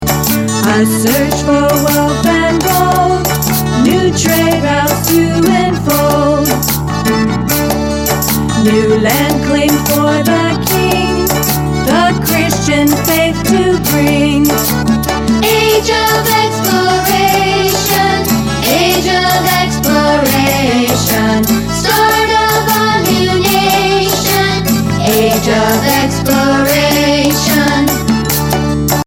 mp3 Vocal Song Track